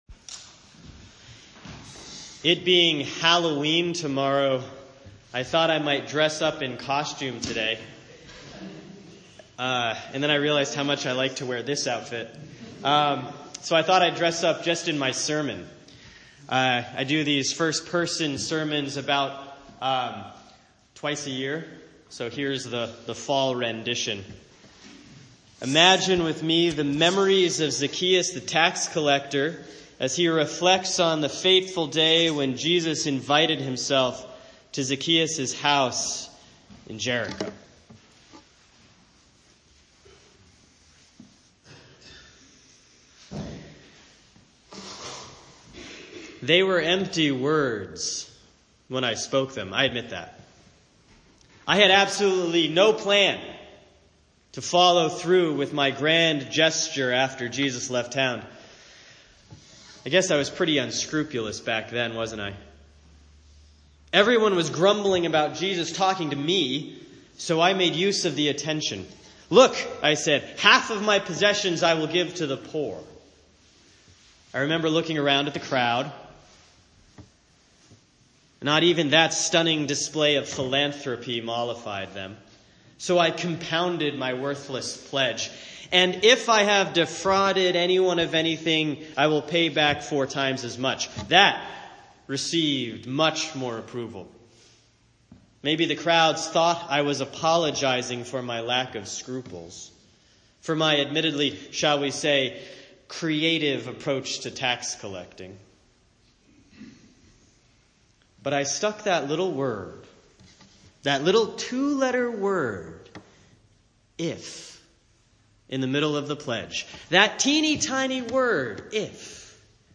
A first person narrative of the story of Zaccheaus, who finally learns to take responsibility in his life after meeting Jesus.